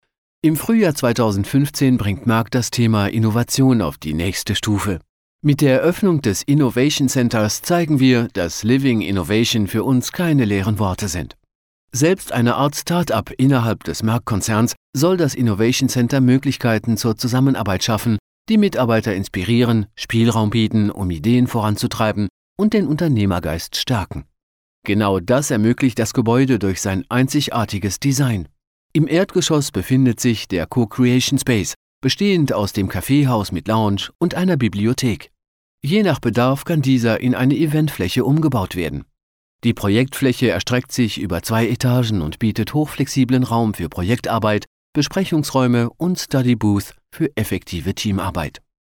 Sprechprobe: Industrie (Muttersprache):
Professional Fulltime Versatile Native German and French Voice Over Talent (bilingual by birth) I do- French & German recordings as a native but also offer accented readings in English Age Range 35 - 45 • Young Adult Male • Middle Age Male Voice Description Neutral Friendly Cool Corporate Upscale Sophisticated Cartoonish Fresh Hard Sell My Services • Commercials • Promos • IVR, voicemail, phone systems, and on-hold messages • Training, business presentations, sales, and web sites • Audiobooks • Videogames • Documentaries • TV shows and movies • Movie and game trailers • Podcasts